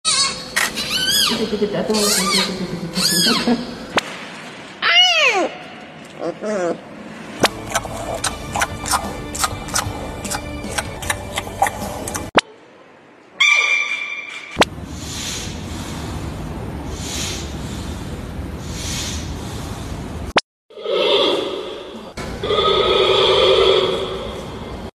How does panda’s cry sound sound effects free download
Different stages of panda’s life.Newborn panda to adult panda.